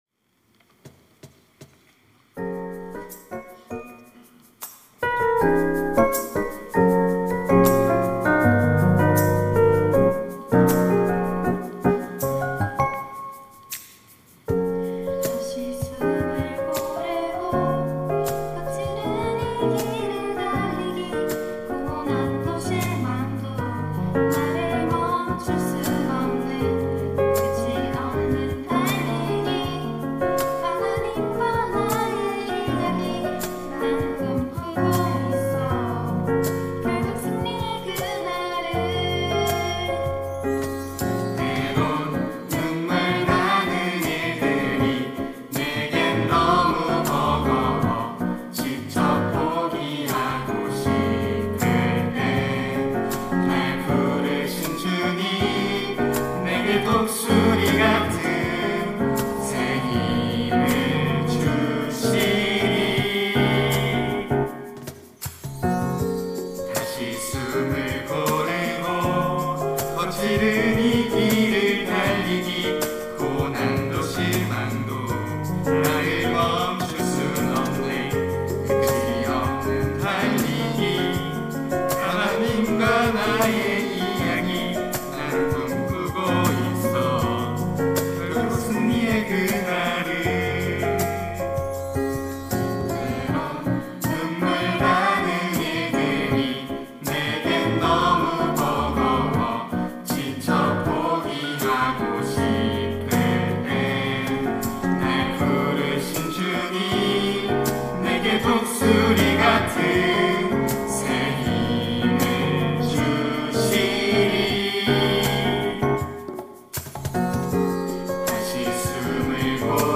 특송과 특주 - 달리기
청년부 4팀 6셀